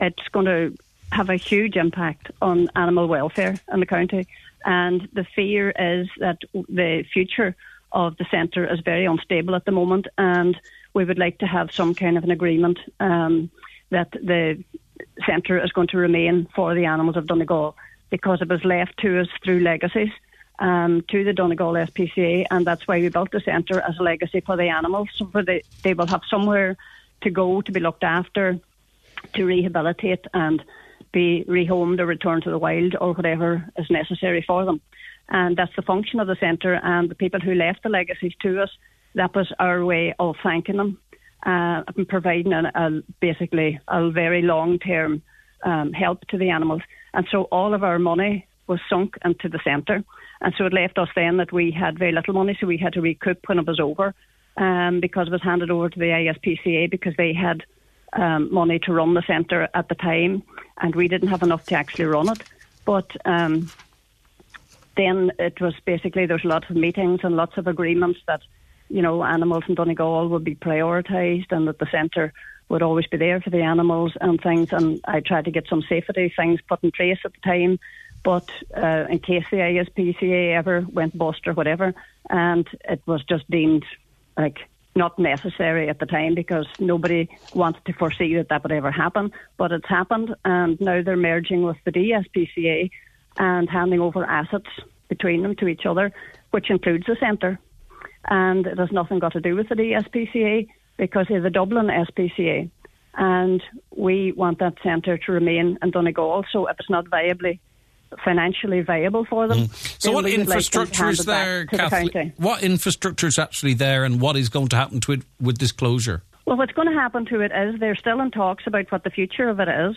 On today’s Nine til Noon Show, she urged people to show their support later this week……..